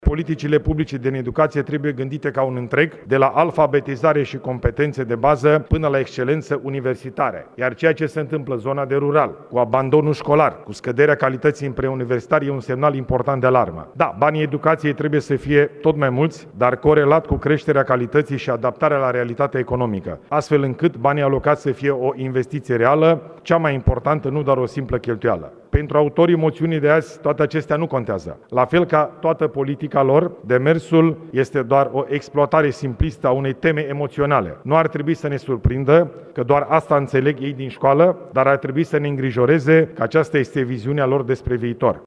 Premierul Ilie Bolojan: „Politicile publice din educație trebuie gândite ca un întreg, de la alfabetizare și competențe de bază până la excelență universitară”
De la tribuna Senatului, Ilie Bolojan a vorbit despre finanțarea educației și, printre altele, a spus că statul oferă studenților atâtea burse cât își permite. Premierul i-a criticat pe parlamentarii opoziției, despre care a spus că exploatează simplist o temă emoțională.